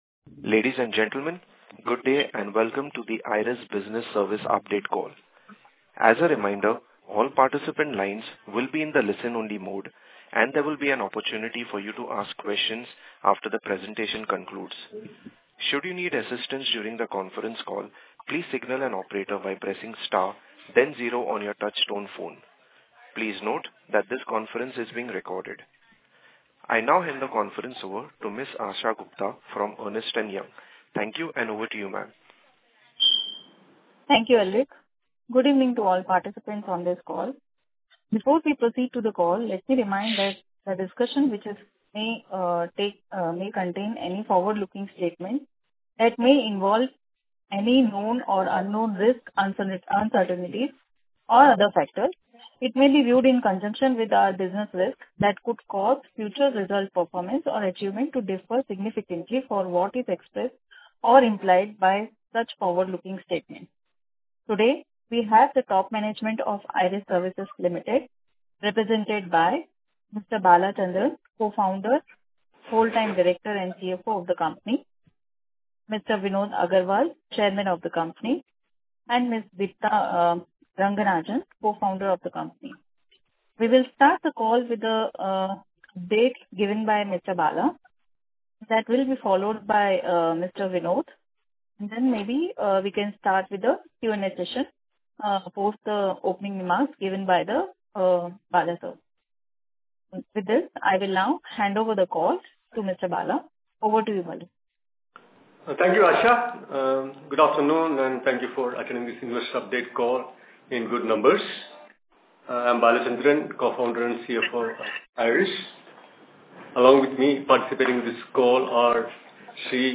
Audio Recording of the Conference Call for Company Update held on April 25, 2025.
recordingofconferencecall.mp3